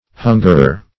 Hungerer \Hun"ger*er\, n. One who hungers; one who longs.
hungerer.mp3